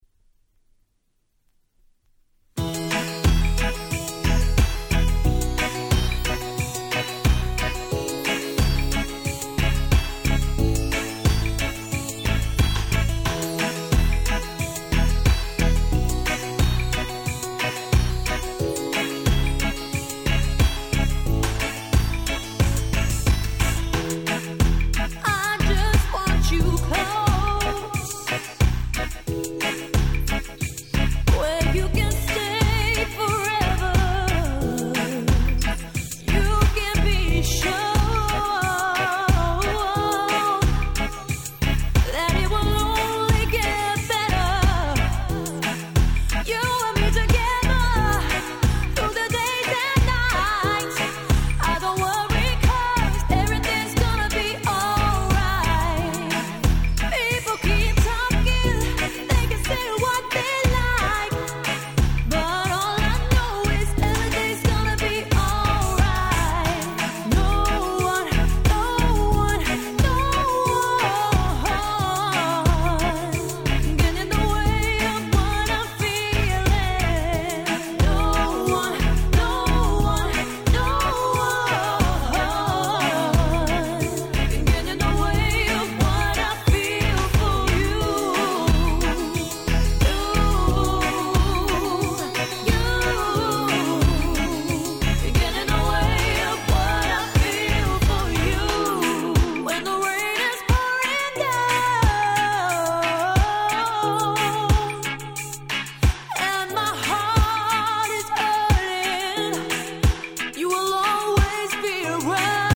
Lovers Reggae ラバーズレゲエ